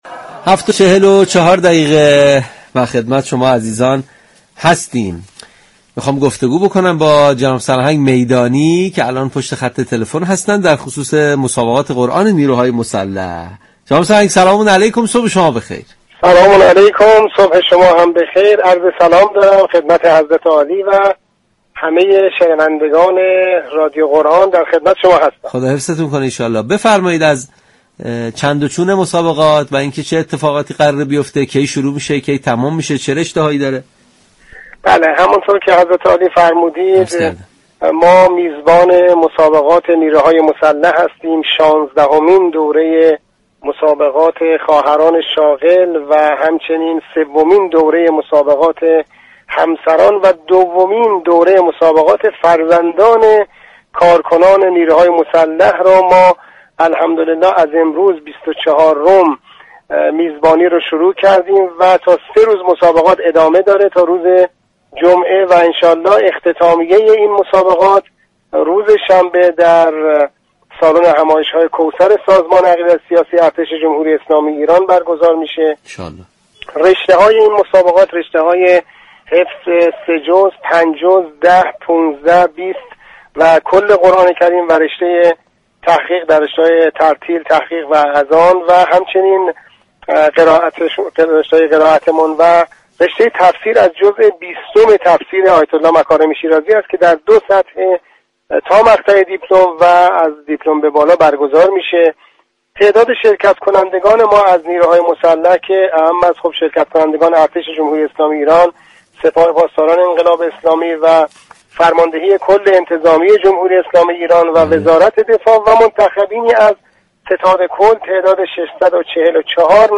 در گفتگو با برنامه تسنیم رادیو قرآن گفت: